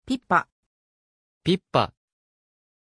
Pronunciation of Pippah
pronunciation-pippah-ja.mp3